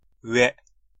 Käännös Ääninäyte Substantiivit 1.
IPA : [stɑɹt]